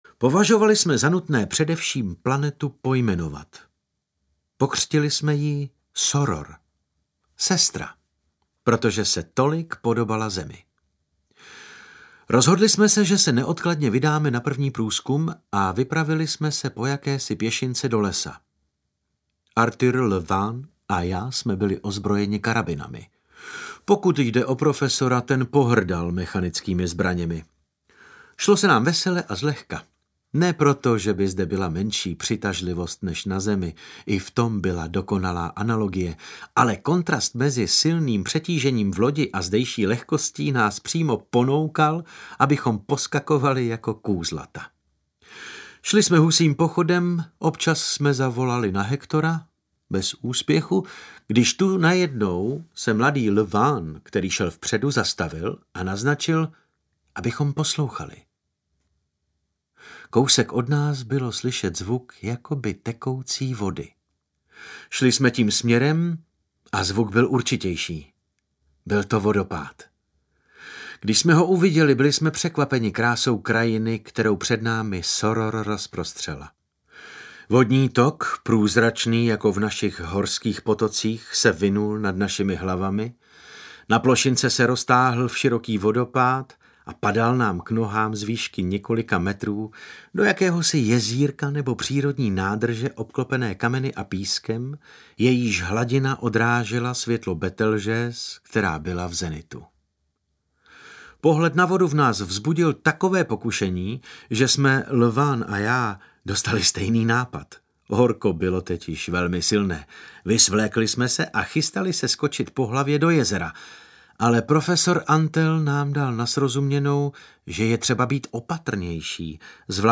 Audioknihy